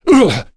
Ricardo-Vox_Damage_01.wav